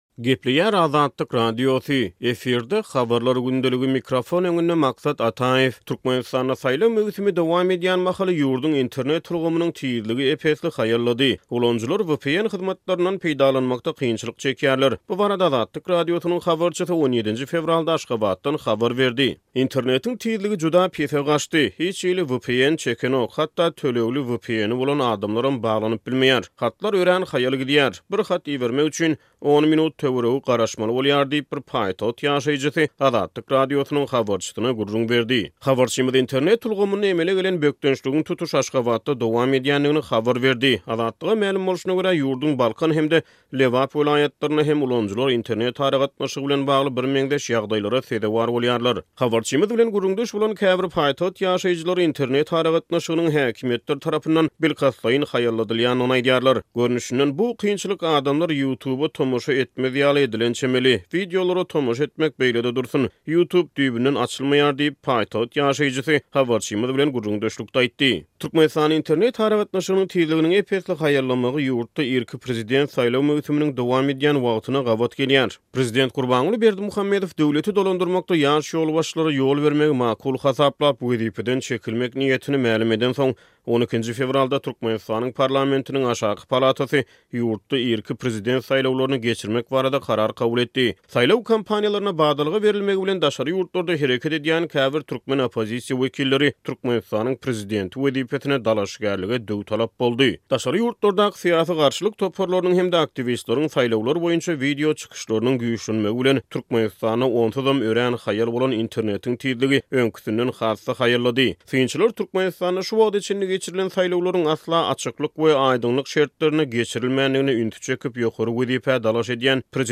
Türkmenistanda saýlaw möwsümi dowam edýän mahaly, ýurduň Internet ulgamynyň tizligi ep-esli haýallady. Ulanyjylar VPN hyzmatlaryndan peýdalanmakda kynçylyk çekýärler. Bu barada Azatlyk Radiosynyň habarçysy 17-nji fewralda Aşgabatdan habar berdi.